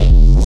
Bass 1 Shots (108).wav